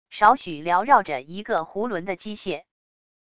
少许缭绕着一个弧轮的机械wav音频